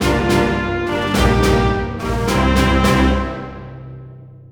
victory.wav